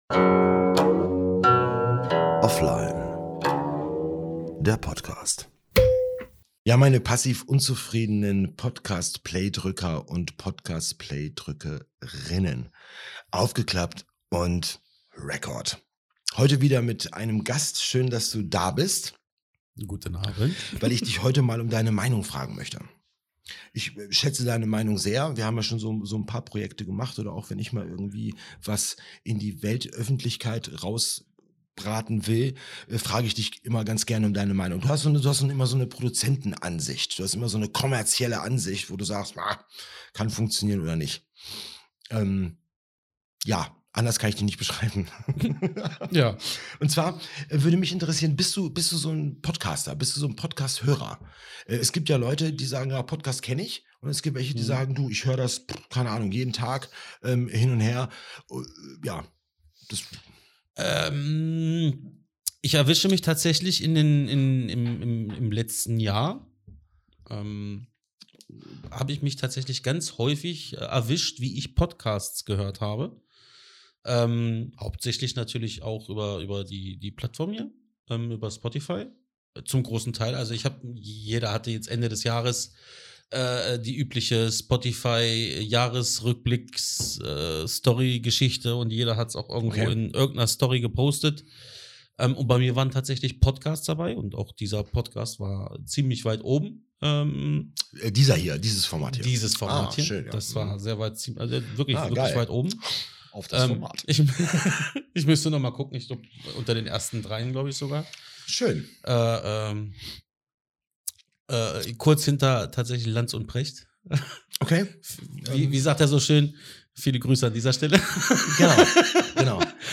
Ein geselliges Gespräch aus der Kategorie: Lach- und Sachgeschichten